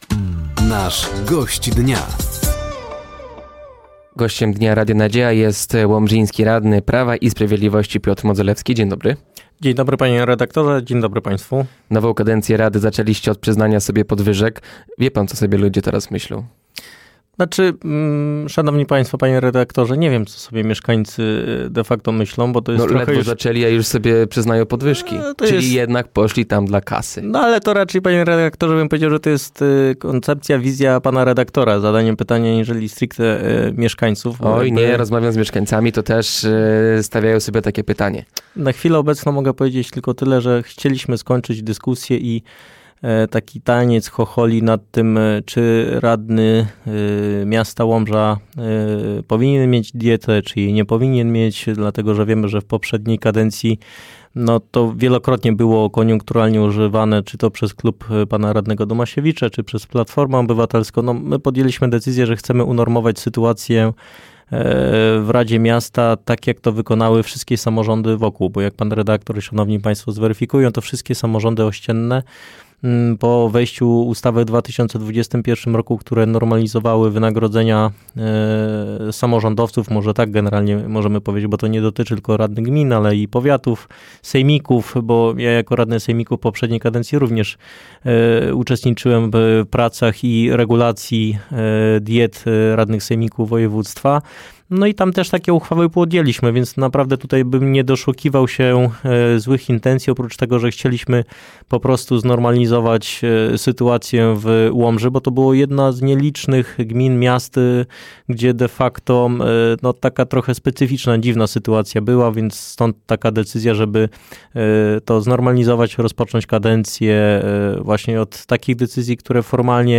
Gościem Dnia Radia Nadzieja był Piotr Modzelewski, radny Rady Miejskiej Łomży. Tematem rozmowy były między innymi podwyżki, które przyznali sobie radni na ostatniej sesji, komisje oraz rezygnacja Krzysztofa Jurgiela z członkostwa w Prawie i Sprawiedliwości.